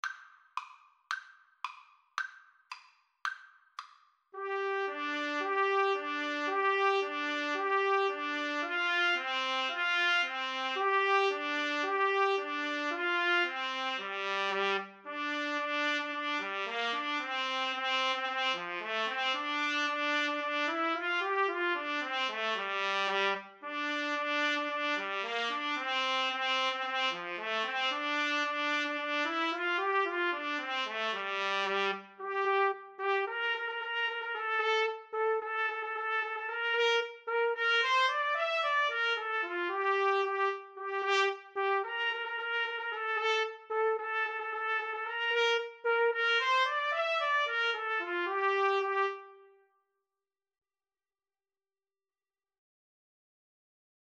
2/4 (View more 2/4 Music)
G minor (Sounding Pitch) A minor (Trumpet in Bb) (View more G minor Music for Trumpet Duet )
Allegro moderato = c. 112 (View more music marked Allegro)
Trumpet Duet  (View more Easy Trumpet Duet Music)